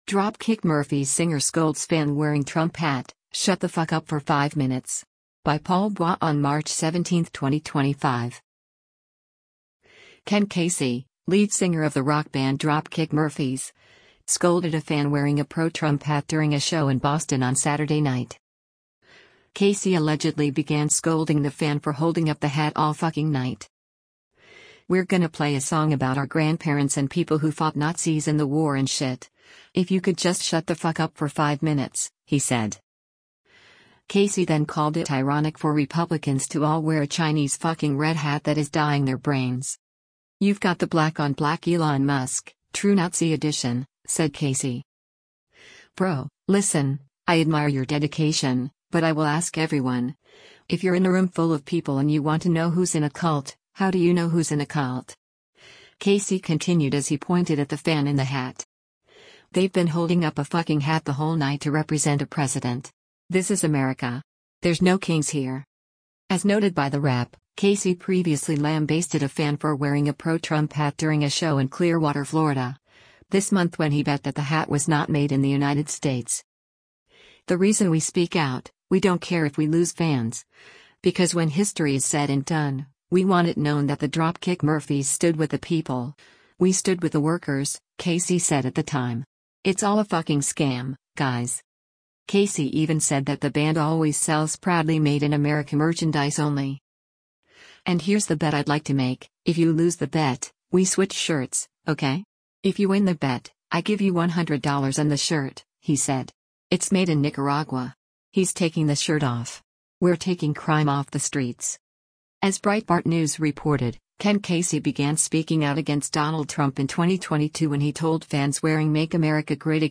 Ken Casey, lead singer of the rock band Dropkick Murphys, scolded a fan wearing a pro-Trump hat during a show in Boston on Saturday night.